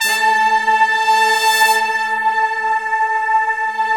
SI1 BRASS0HR.wav